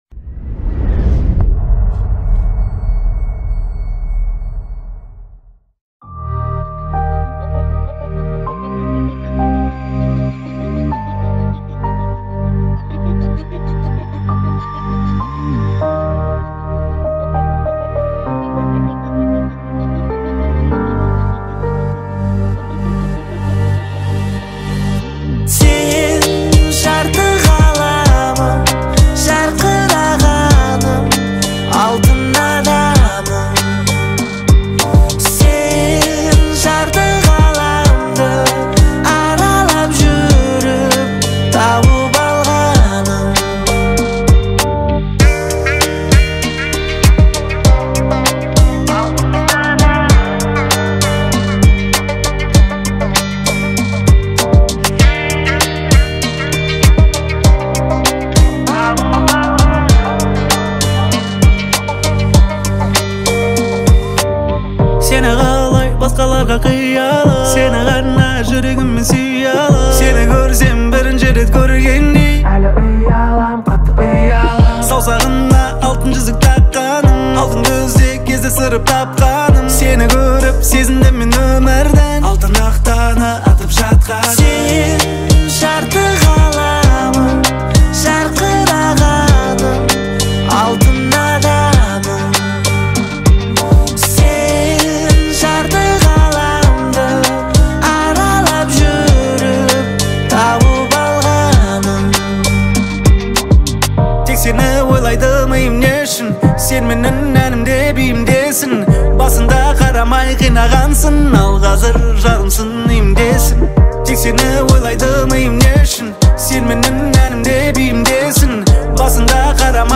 Скачать музыку / Музон / Казахская музыка 2024